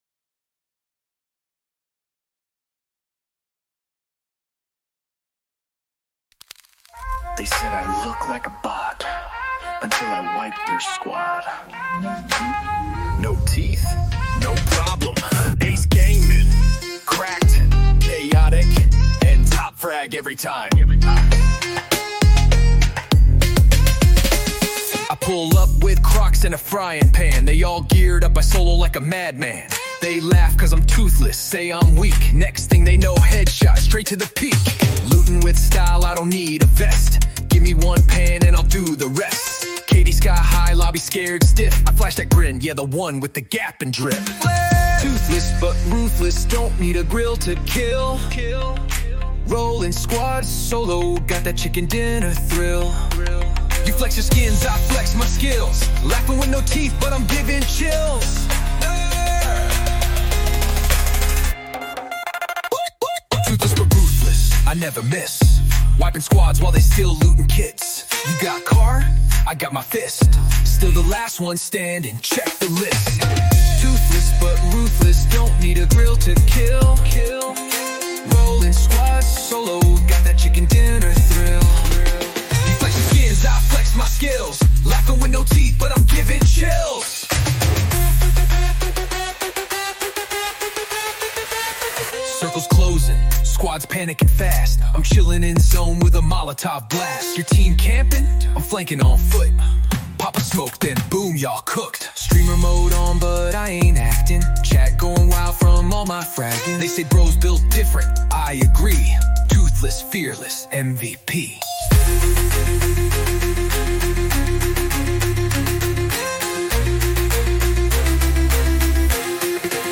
Comedy meets clutch plays in this epic rap banger.